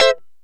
Lng Gtr Chik Min 05-A2.wav